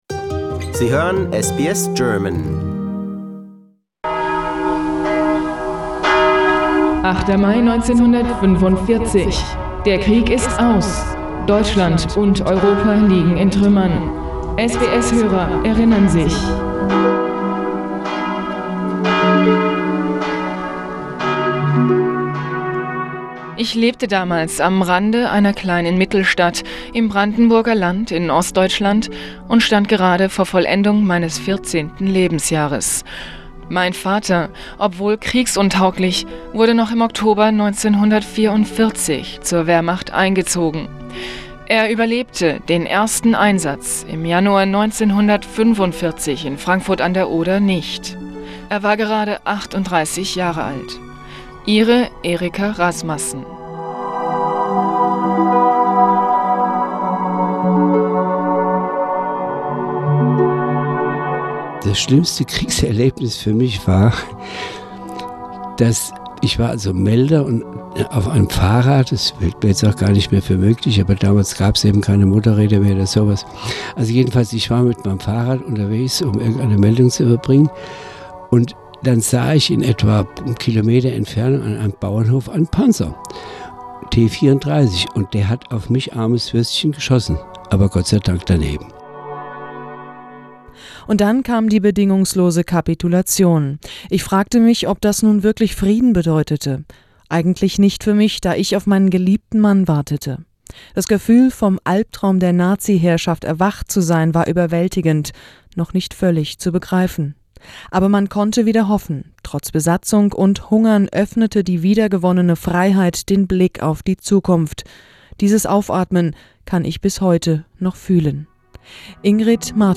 PC 9: An audio montage with key quotes from all participants.